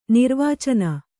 ♪ nirvācana